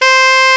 SAX A.FF C0G.wav